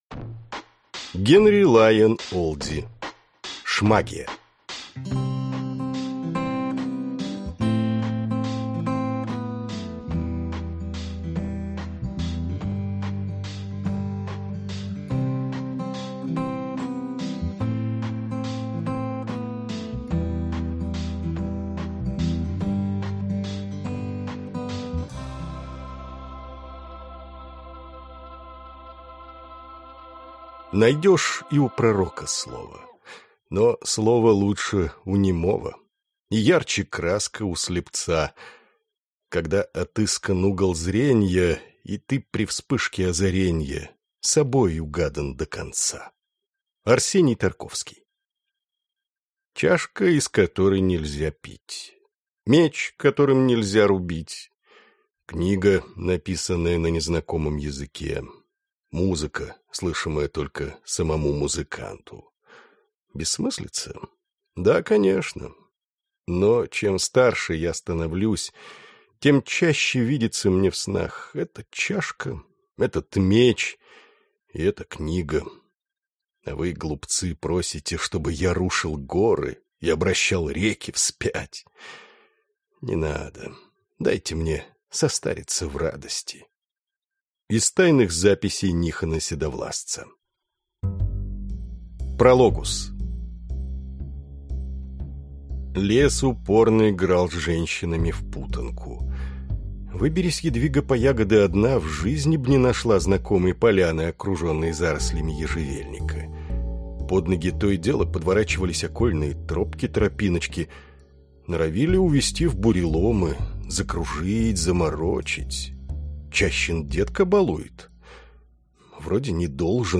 Аудиокнига Шмагия | Библиотека аудиокниг